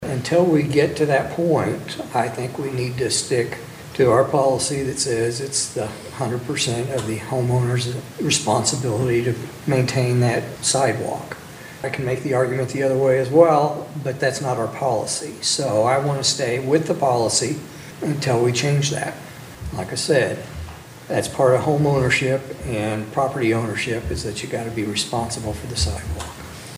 Commissioner Scott Smith